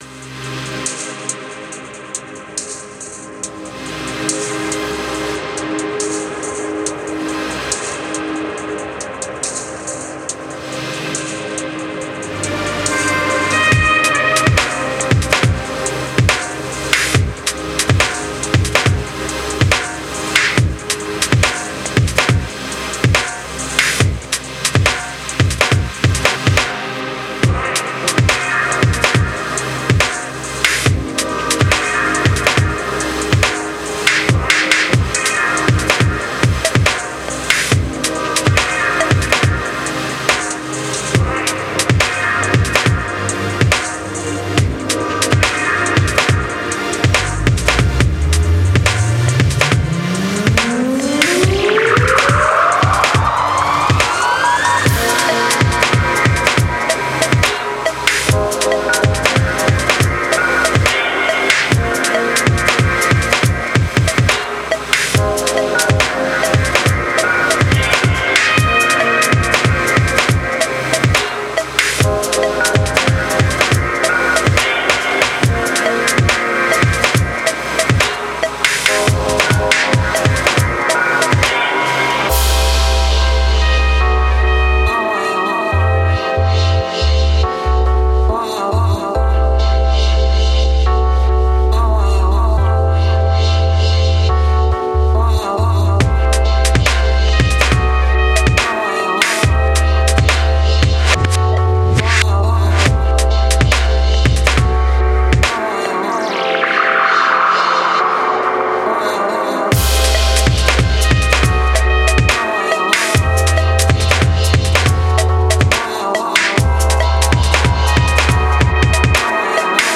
Genre: IDM.